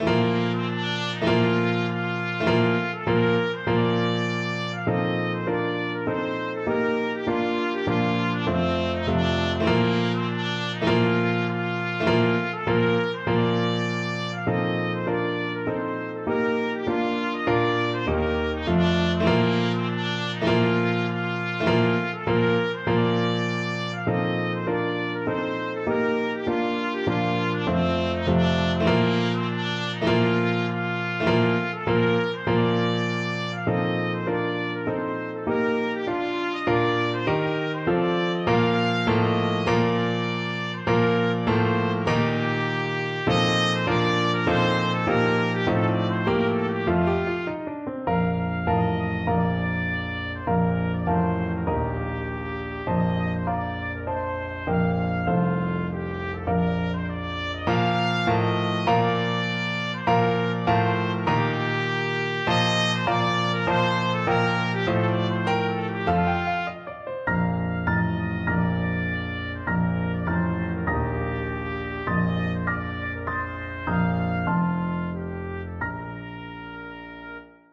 March = c.100
4/4 (View more 4/4 Music)
Bb major (Sounding Pitch) C major (Trumpet in Bb) (View more Bb major Music for Trumpet )
Trumpet  (View more Intermediate Trumpet Music)
Traditional (View more Traditional Trumpet Music)
Scottish